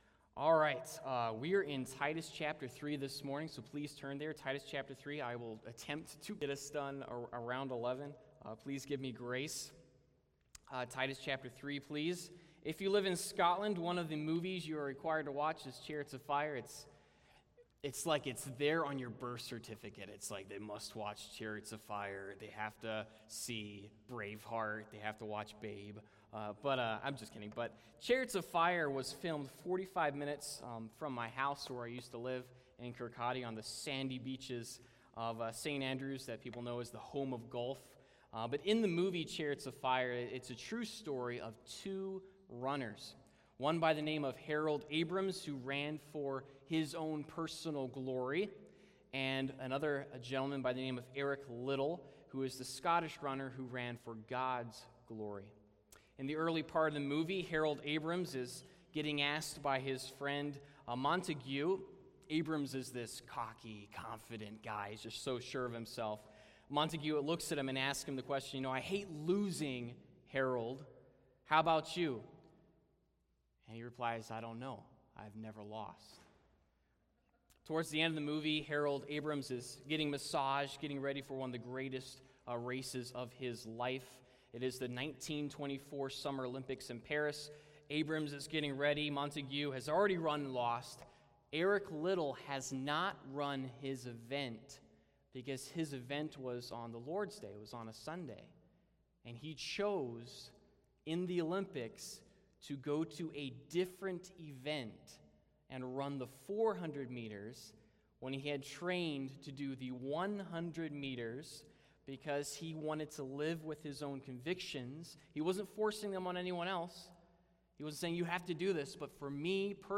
Titus 3:1-8 Service Type: Sunday Morning What is central to your life?